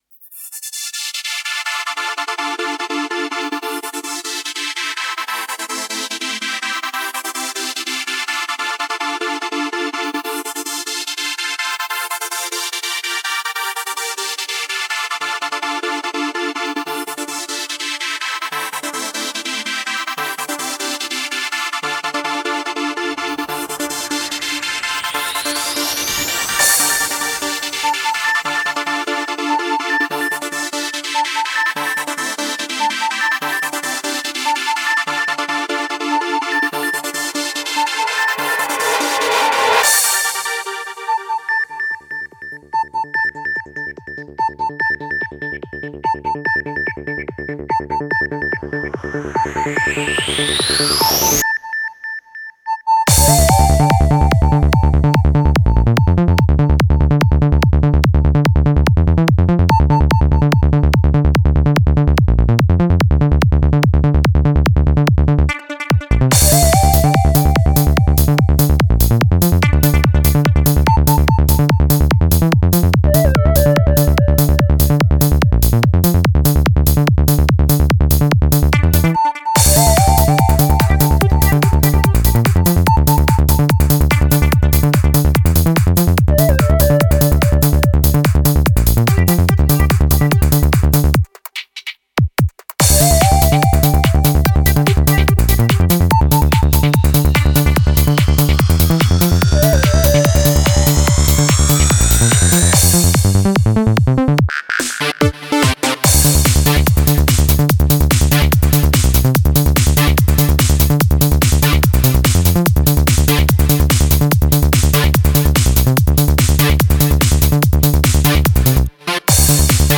Style: Full On
Quality: 320 kbps / 44,100 Hz / Full Stereo